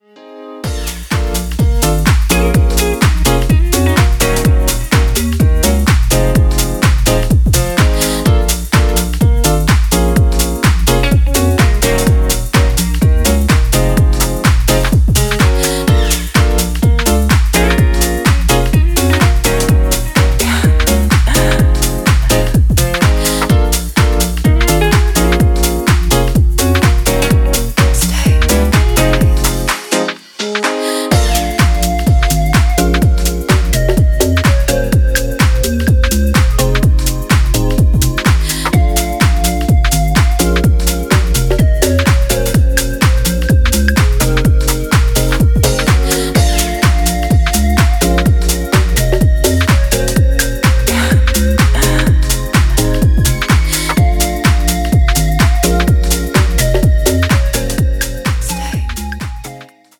エレピのリードやホーンが軽快に差し込まれるフュージョン・タッチのディープ・ハウス